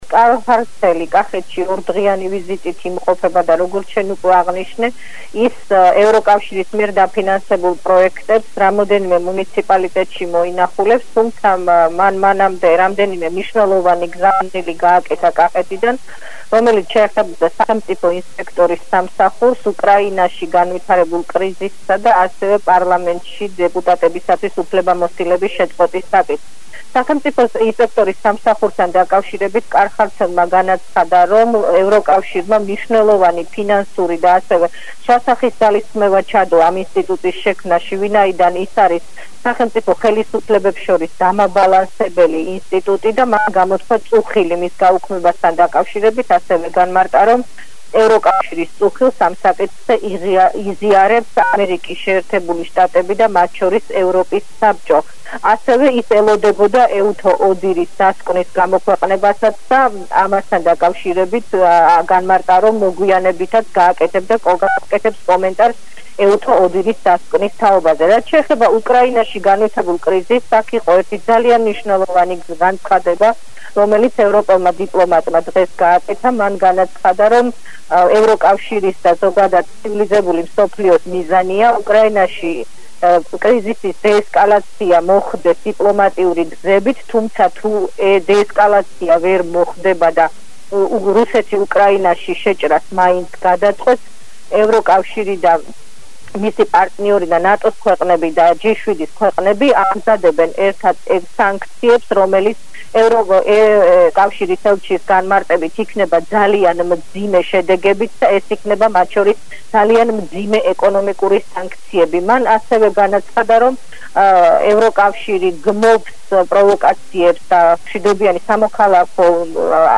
ჩართვას ახალ ამბებში